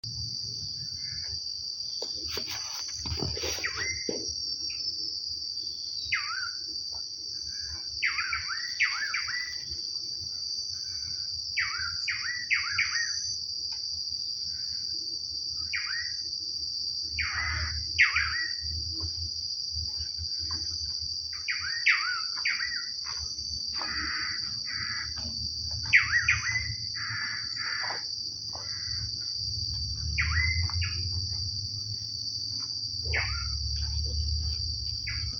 Tangará (Chiroxiphia caudata)
Nome em Inglês: Blue Manakin
Detalhada localização: Reserva Natural Silvestre Parque Federal Campo San Juan
Condição: Selvagem
Certeza: Fotografado, Gravado Vocal